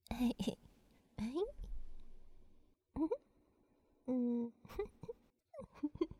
笑1.wav
笑1.wav 0:00.00 0:06.20 笑1.wav WAV · 534 KB · 單聲道 (1ch) 下载文件 本站所有音效均采用 CC0 授权 ，可免费用于商业与个人项目，无需署名。
人声采集素材/人物休闲/笑1.wav